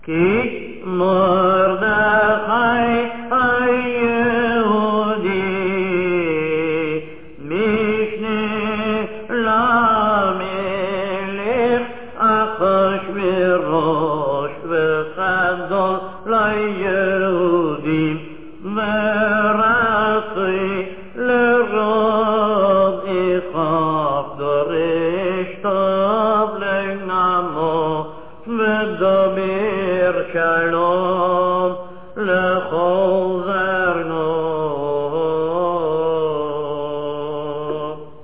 The following verses are sung by the kahal, and repeated by the Chazzan: